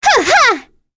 Cleo-Vox_Attack3.wav